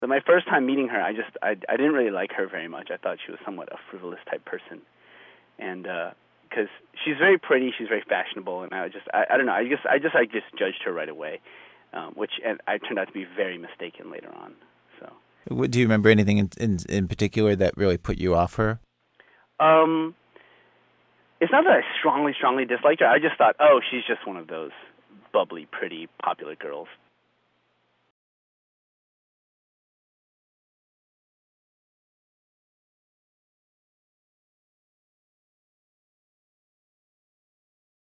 That seemed like something he should probably ask, not me, so we arranged for me to interview him over the phone two days before the speech.